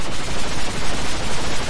描述：记录自arp2600va free vsti，随机参数算法在flstudio 3.0中
Tag: 合成器 发电机 振荡器